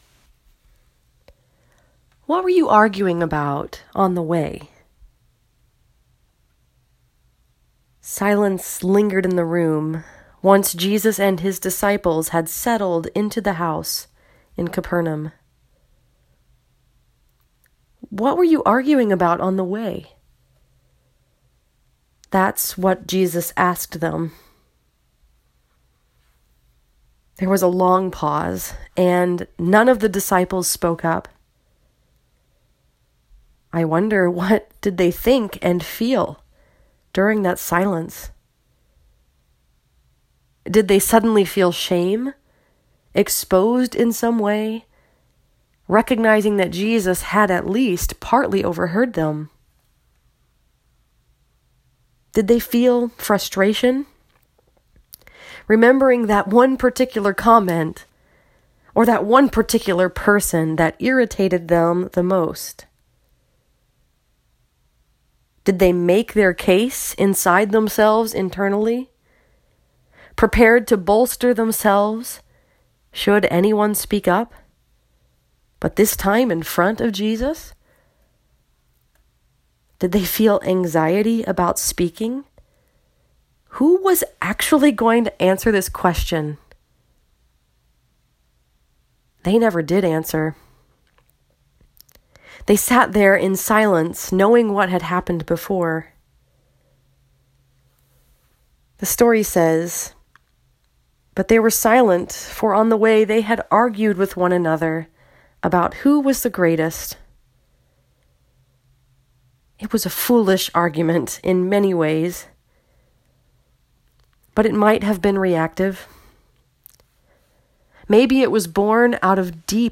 This sermon was preached at Kirk of Our Savior Presbyterian Church in Westland, Michigan and was focused upon the story that is told in Mark 9:30-37.